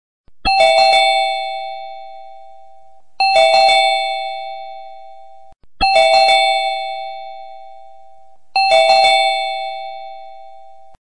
门铃音效
door_bell.mp3